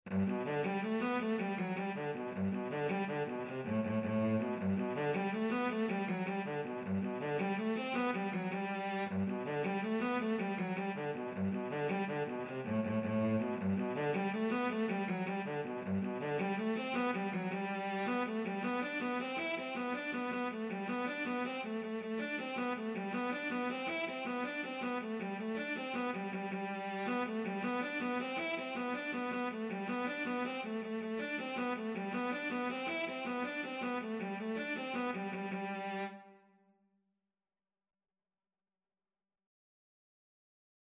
Free Sheet music for Cello
Traditional Music of unknown author.
6/8 (View more 6/8 Music)
G major (Sounding Pitch) (View more G major Music for Cello )
Cello  (View more Intermediate Cello Music)
Traditional (View more Traditional Cello Music)